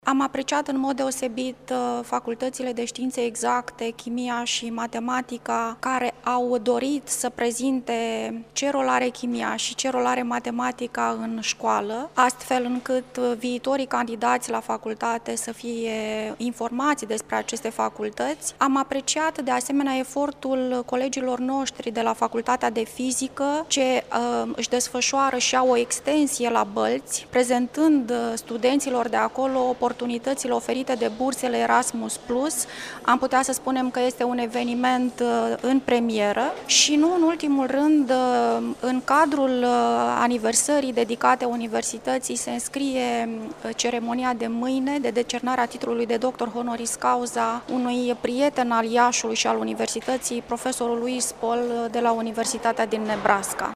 Rectorul interimar al instituţiei, Mihaela Onofrei, a vorbit despre cele mai importante repere ale acestei ediţii: